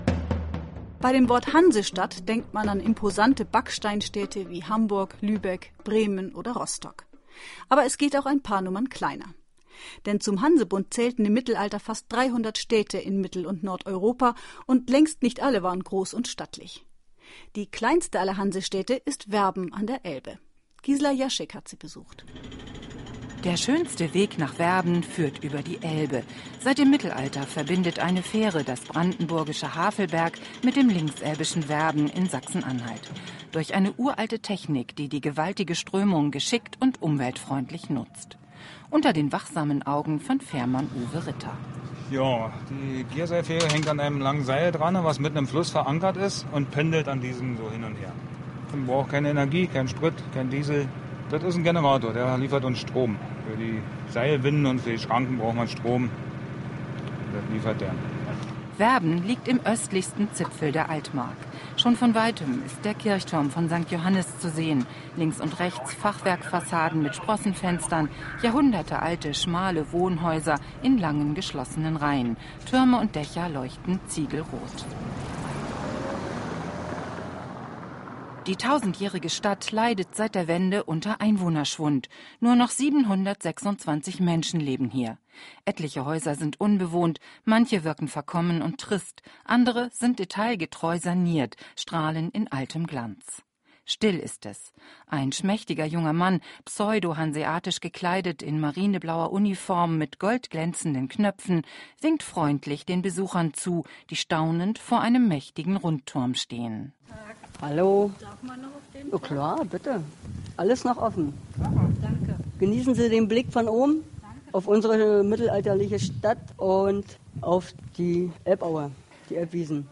Werbener und Zugezogene kommen zu Wort.